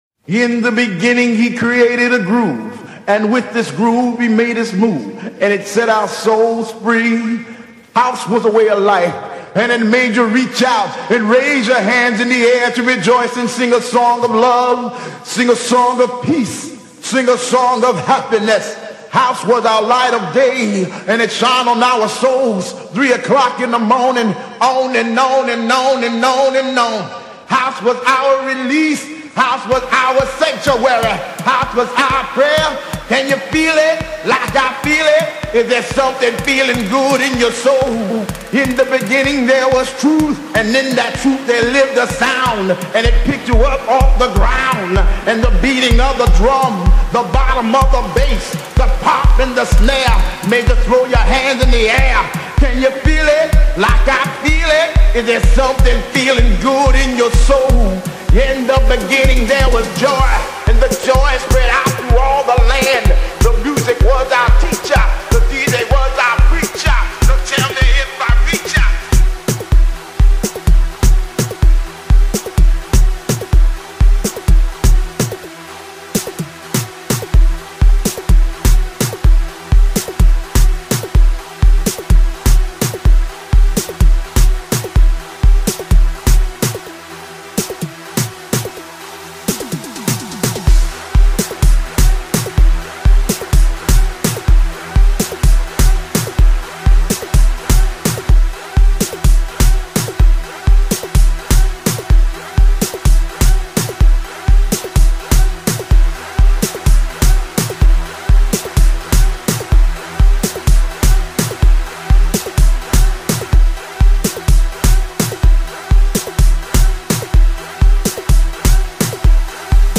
Genre : House